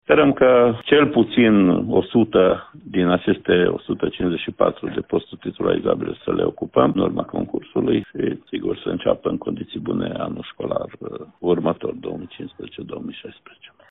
În total sunt scoase la concurs peste 2.800 de posturi, dintre care aproape 1.300 sunt complete, iar 154 sunt titularizabile, a precizat pentru RTM inspectorul școlar general al județului Mureș, Ștefan Someșan.